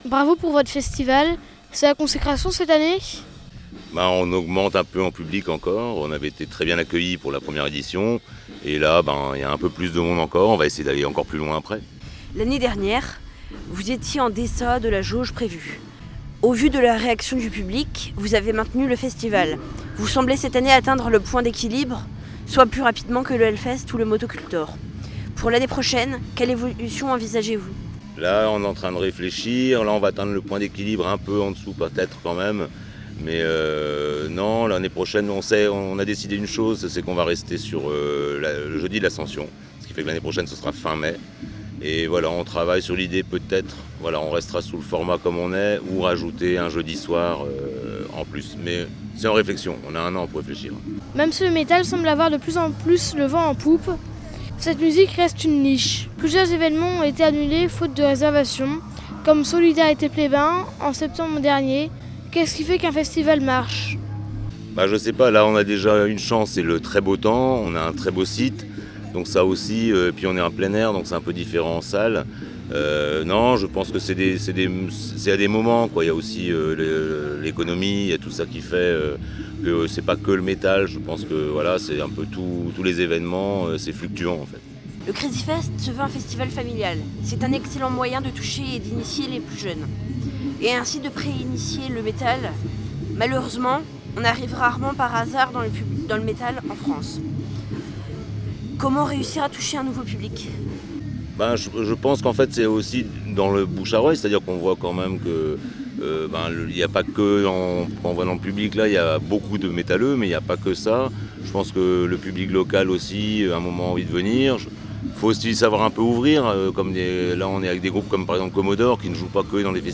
ainsi que quelques interviews de spectateurs.